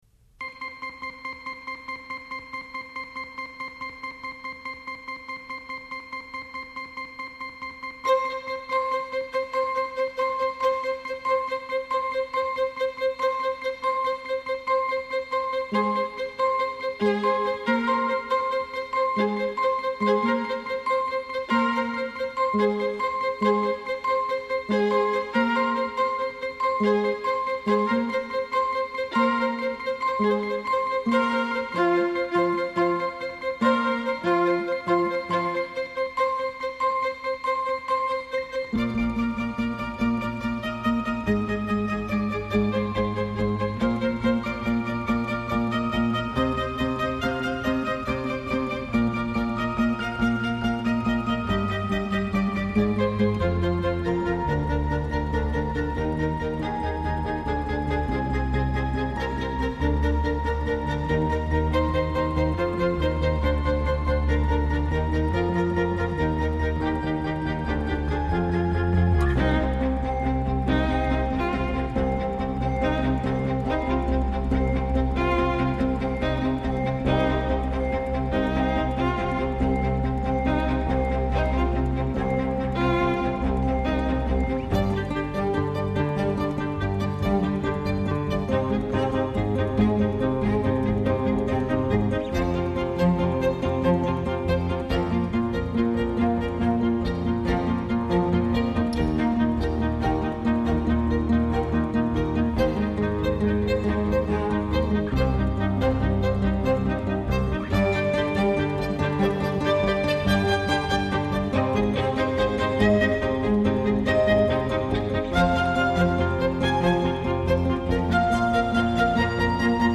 Home > Music > Electronic > Running > Chasing > Hopeful